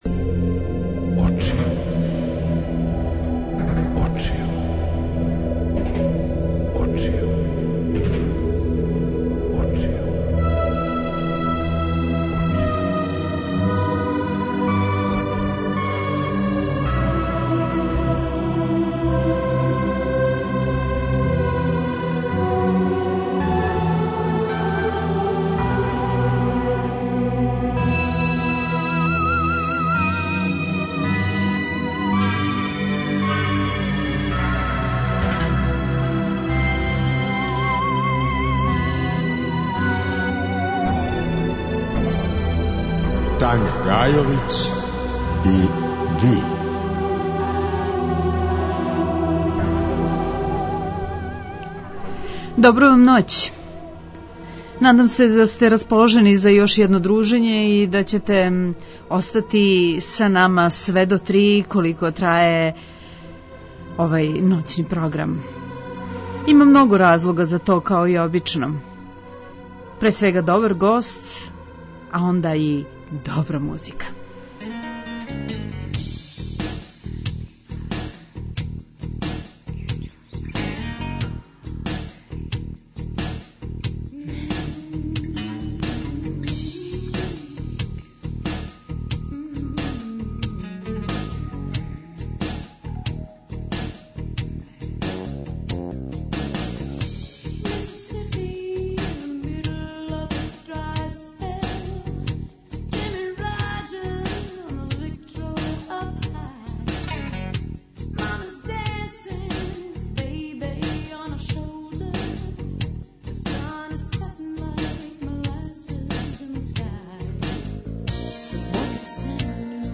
Гост: Бојан Перић, глумац